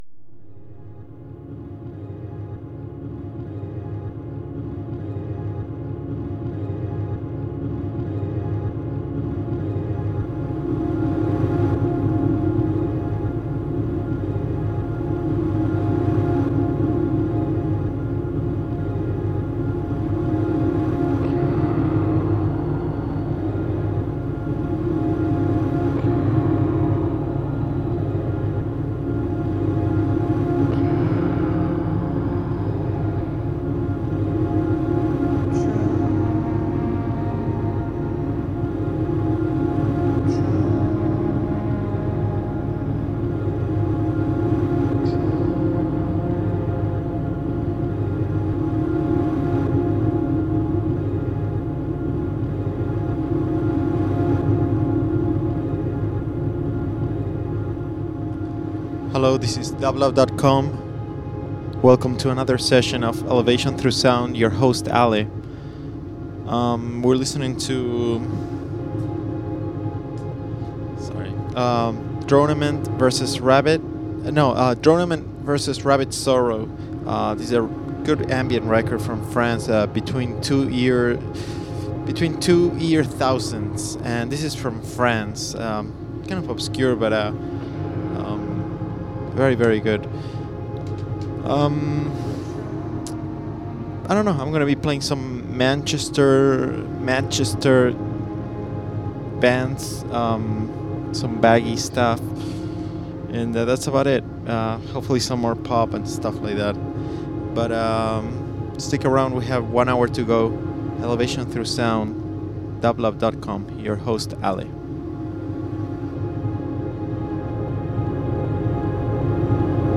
Ambient Indie Jazz